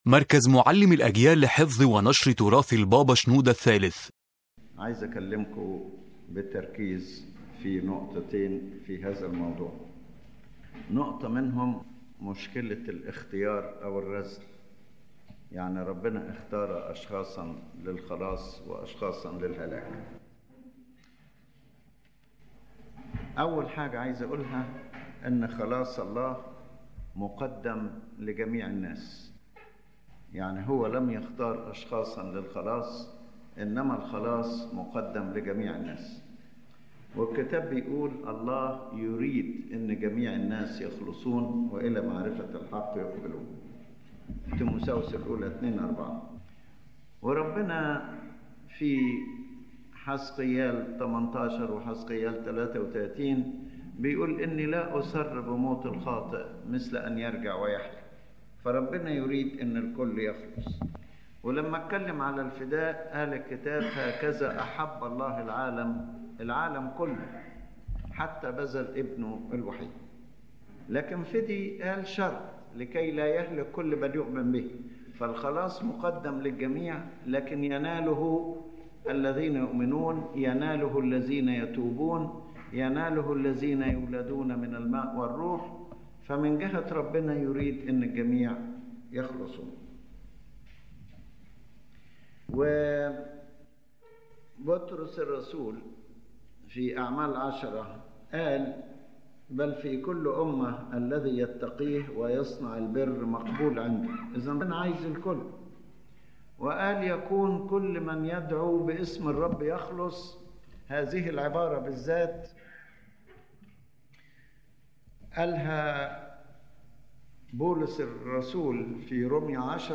The lecture explains the concept of salvation in the Christian faith and clarifies that God does not predetermine some people for salvation and others for destruction. Rather, salvation is offered to every human being.